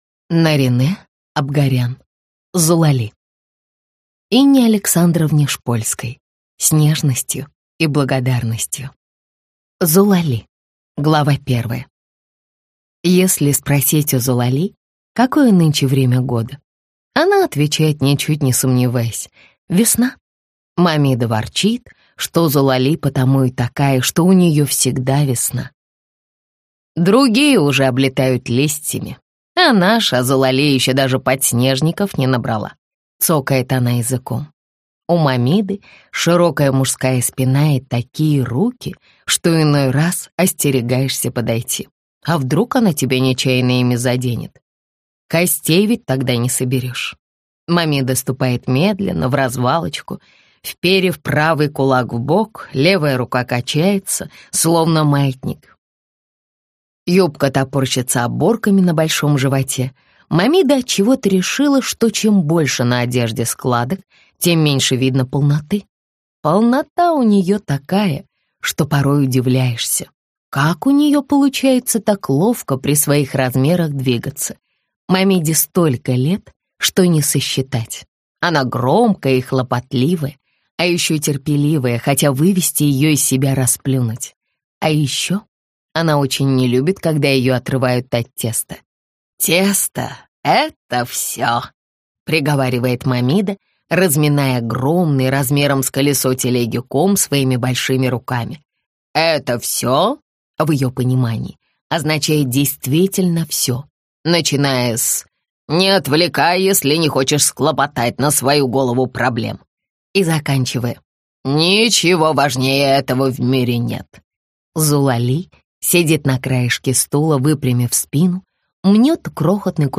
Aудиокнига Зулали